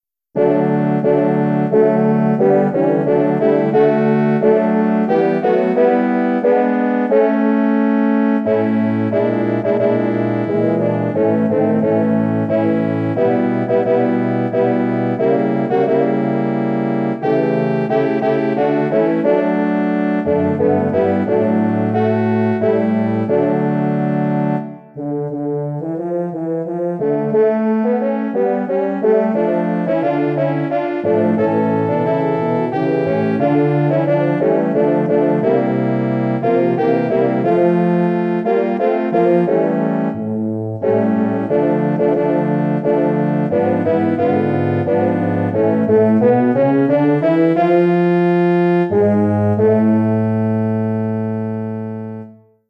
Arrangement / Hornquartett
Bearbeitung für Hornquartett
Besetzung: 4 Hörner
Arrangement for horn quartet
Instrumentation: 4 horns